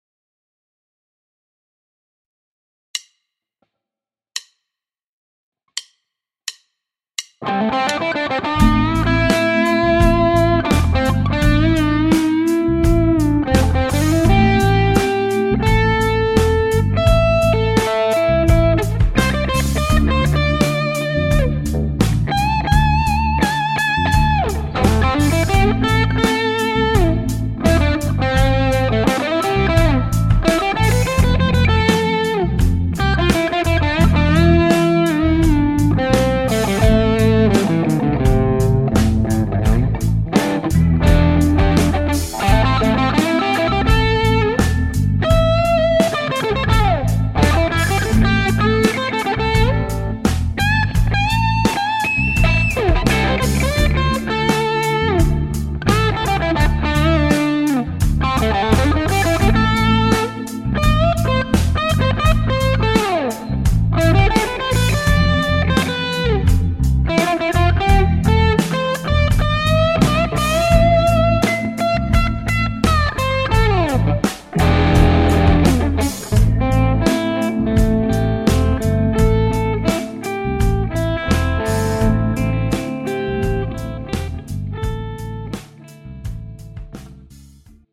A-mollinen tausta löytyy täältä:
- soita soolosi annetun taustan päälle